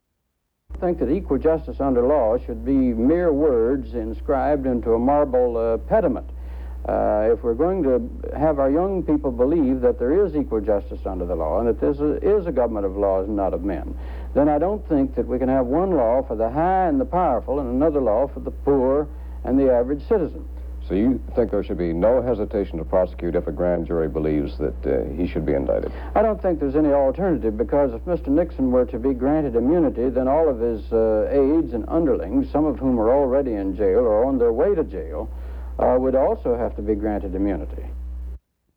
Broadcast on CBS, August 27, 1974.